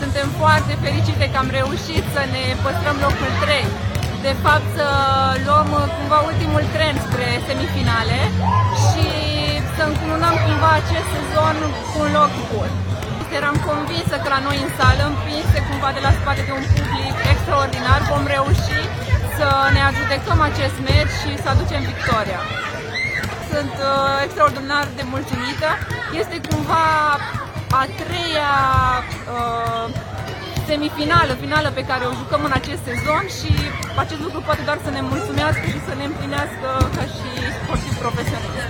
Despre această reușită au vorbit după meci